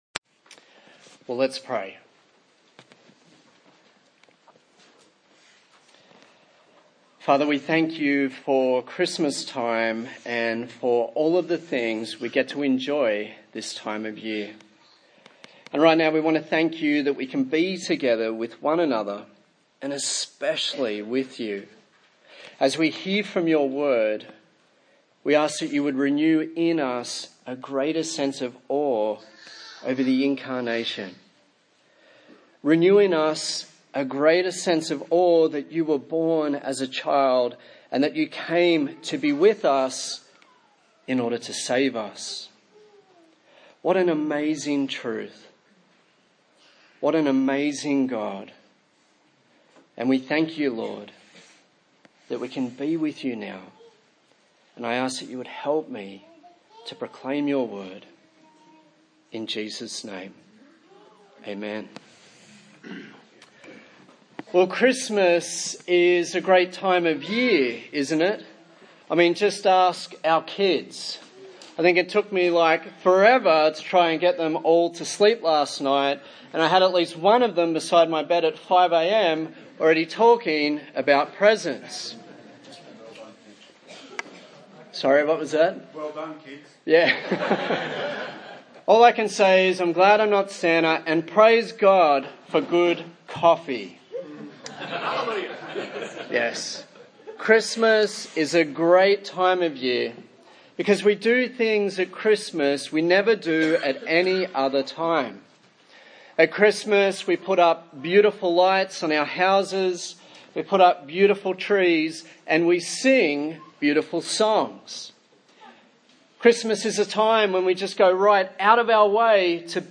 Christmas Passage: Matthew 1:18-25 Service Type: Sunday Morning